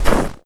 HauntedBloodlines/STEPS Snow, Run 11.wav at main
STEPS Snow, Run 11.wav